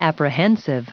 added pronounciation and merriam webster audio
1927_apprehensive.ogg